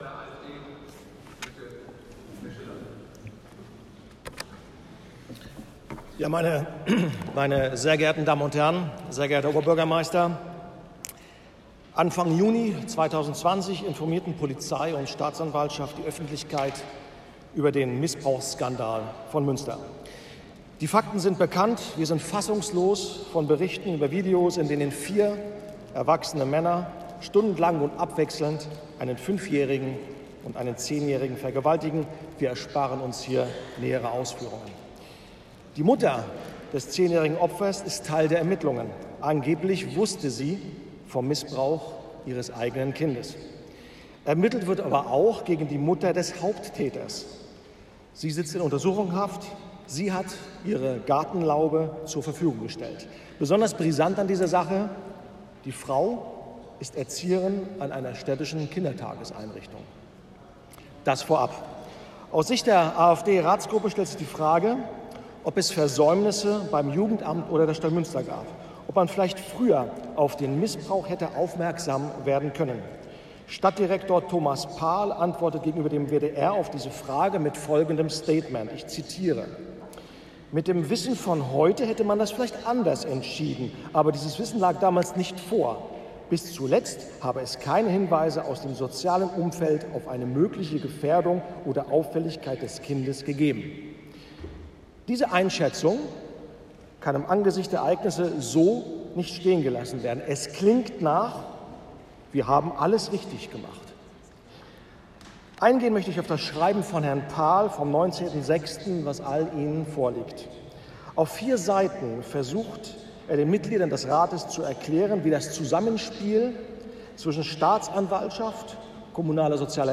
Originalton aus der Ratssitzung vom 24.06.20